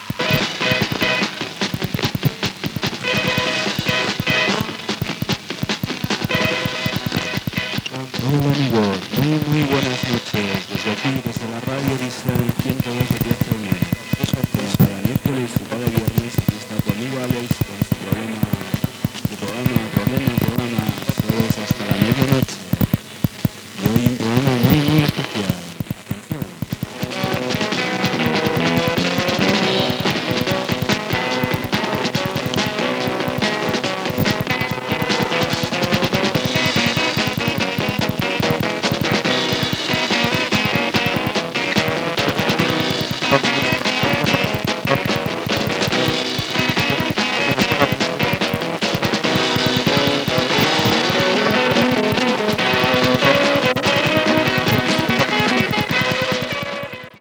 Qualitat del so deficient